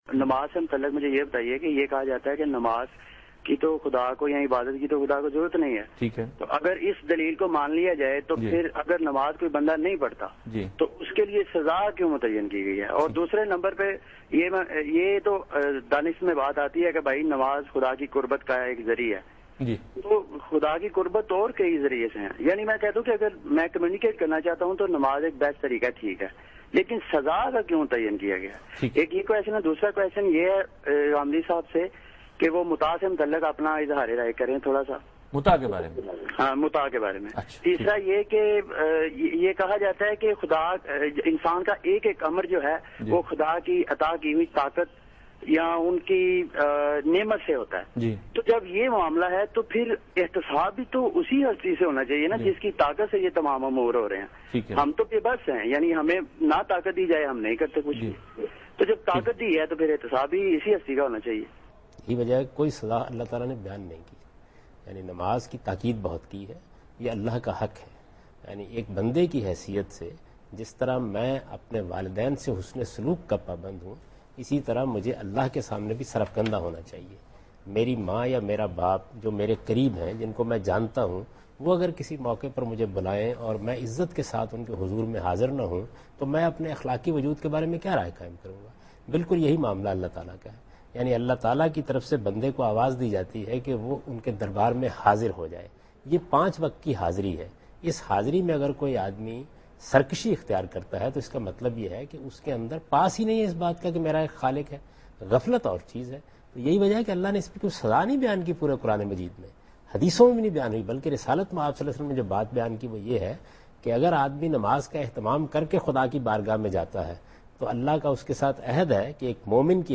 Javed Ahmad Ghamidi Answers a question "Why Punish a Person who does not Pray?" in program Deen o Daanish on Dunya News.
جاوید احمد غامدی دنیا نیوز کے پروگرام دین و دانش میں بے نمازی کو سزا دینے سے متعلق ایک سوال کے جواب دے رہے ہیں۔